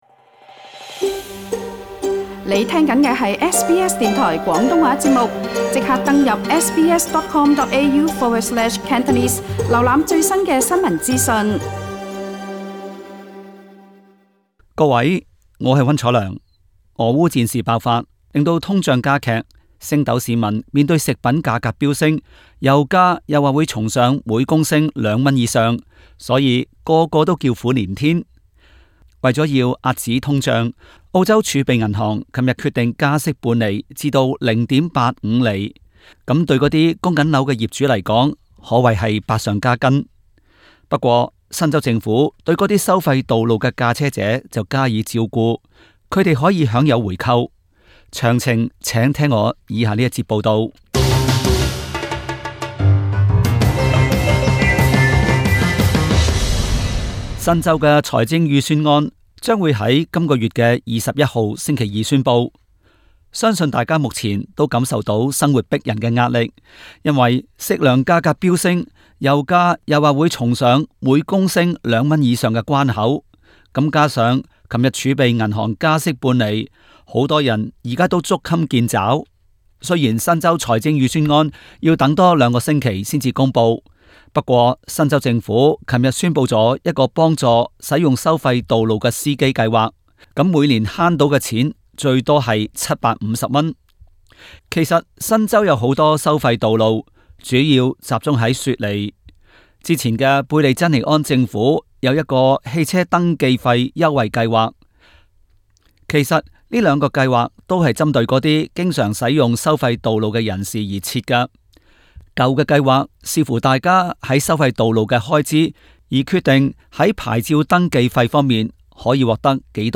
Source: AAP SBS廣東話節目 View Podcast Series Follow and Subscribe Apple Podcasts YouTube Spotify Download (6.6MB) Download the SBS Audio app Available on iOS and Android 大家是否經常使用收費道路的新州市民呢？